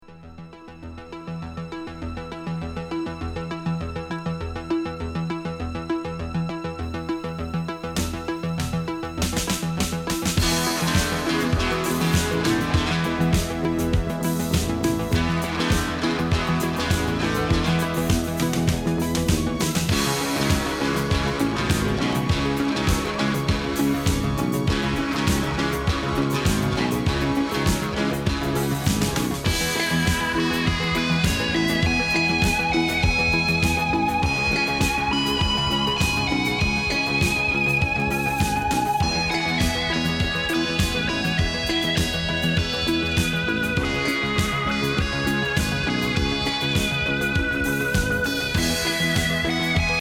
シンセ・スロウ・ファンク